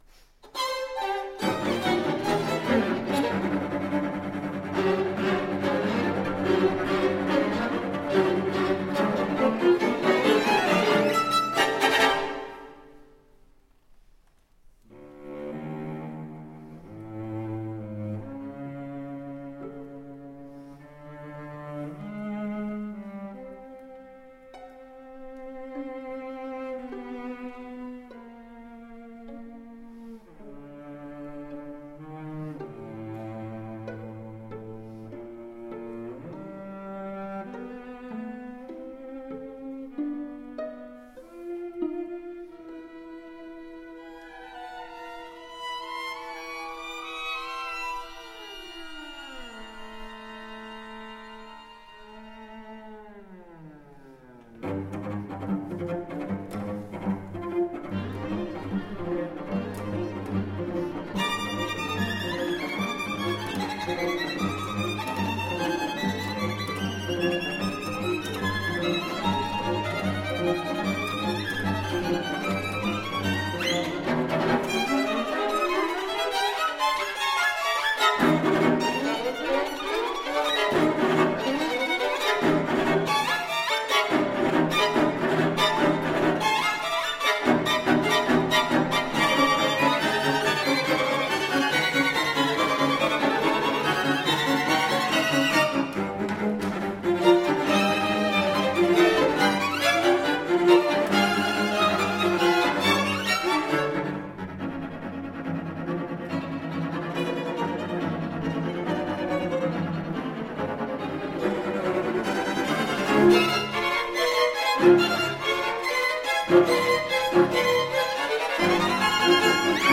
Artist Faculty Concert recordings - July 9, 2014 | Green Mountain Chamber Music Festival
Scherzo for string octet, Op. 11
violin
viola
cello